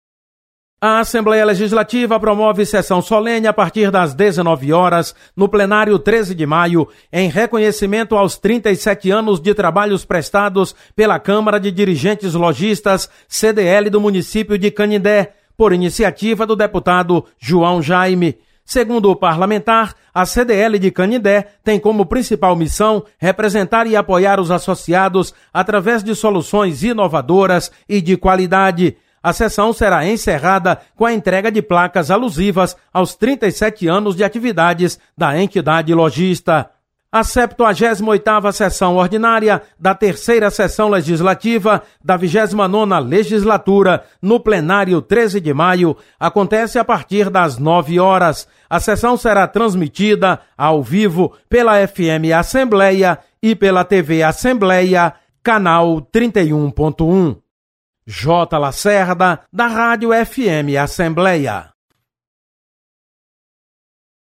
Acompanhe as atividades desta terça-feira (04/07) da Assembleia Legislativa com o repórter